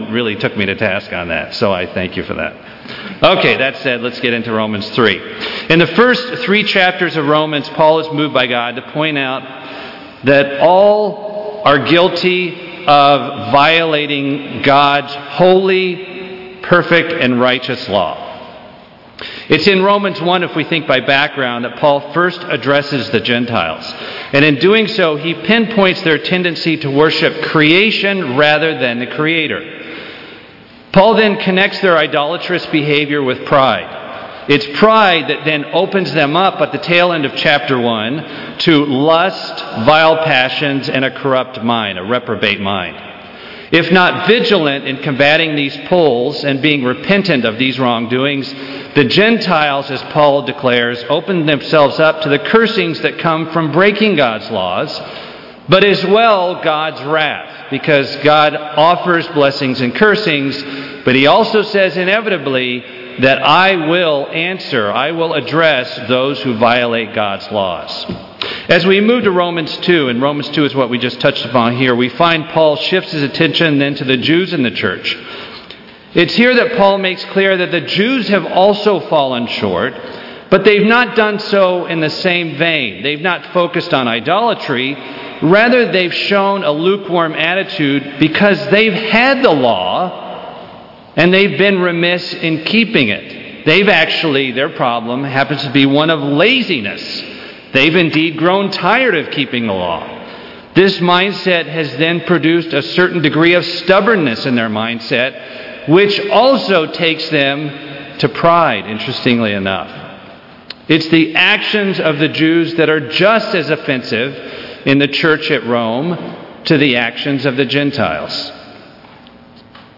Romans sin redemption justification pride idolatry justify Christ sermon Transcript This transcript was generated by AI and may contain errors.